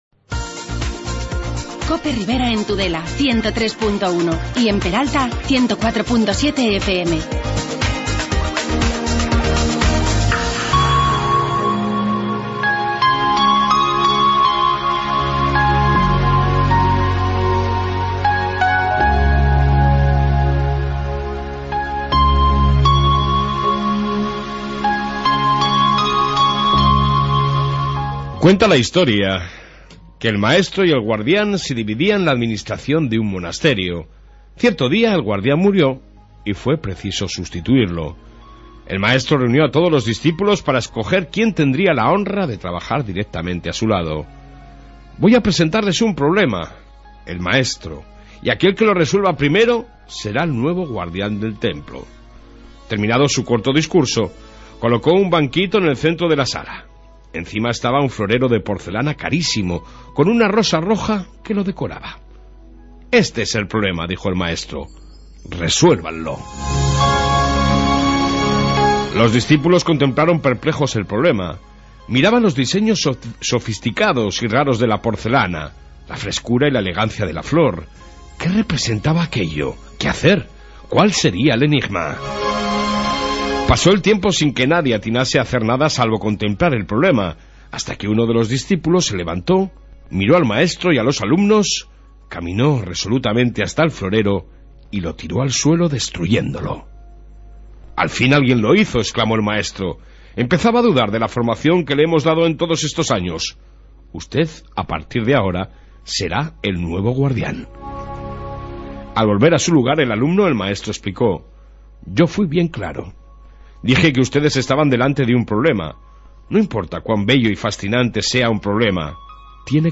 AUDIO: Amplio Informativo Ribero sobre lo ocurrido el fin de semana